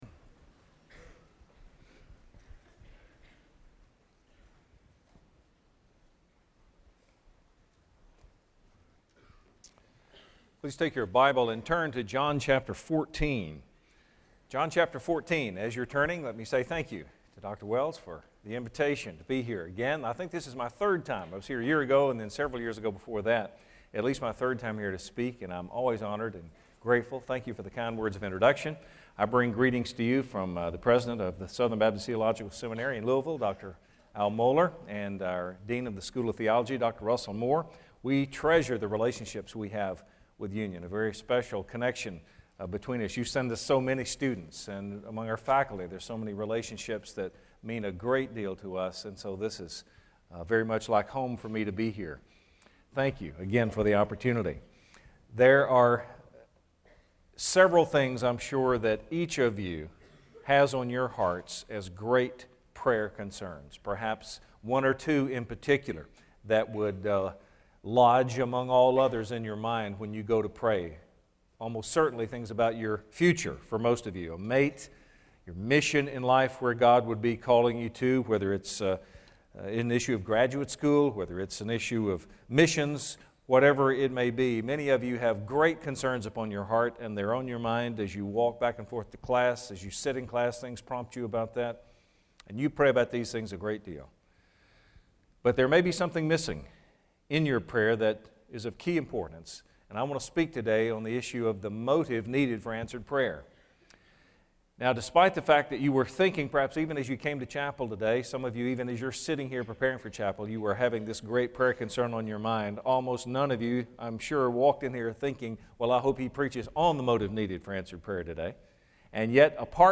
Chapel
Address: The Motive Needed for Prayer